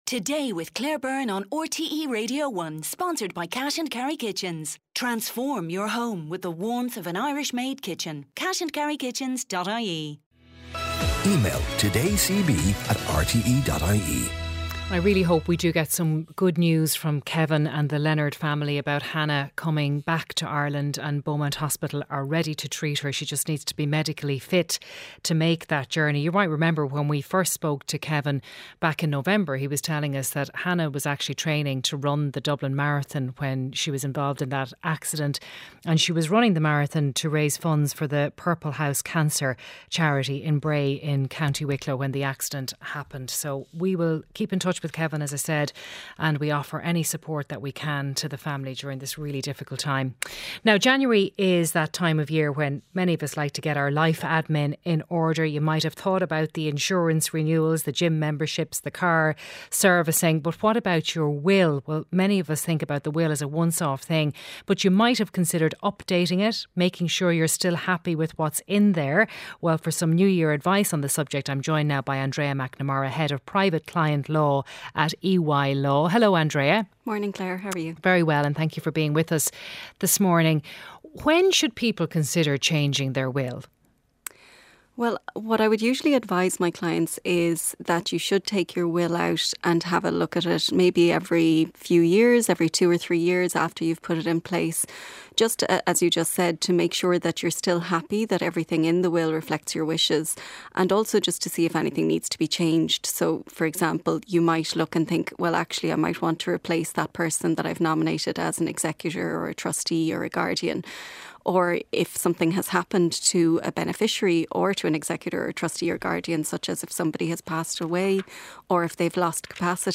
Highlights from the mid-morning show with Claire Byrne, featuring stories of the day, sharp analysis, features, sports and consumer interest items. Listen live Monday to Friday at 10am on RTÉ Radio 1.